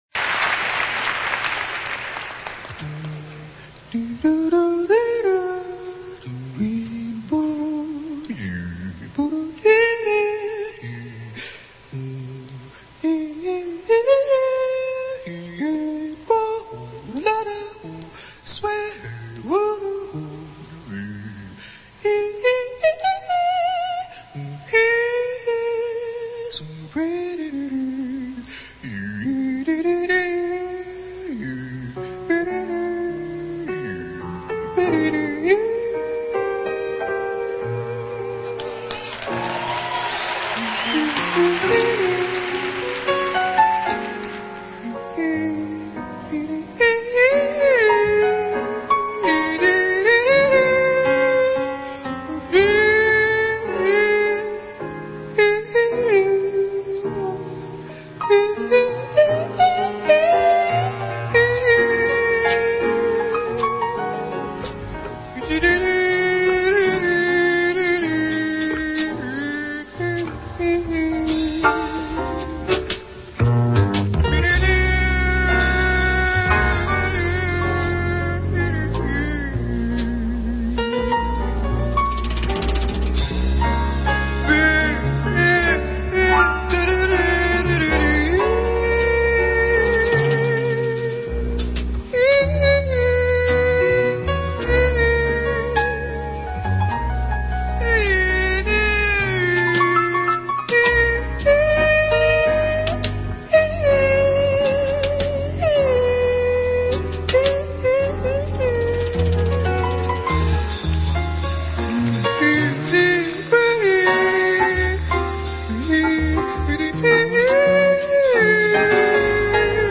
Эту композицию он обычно исполняет в полумраке (скачать):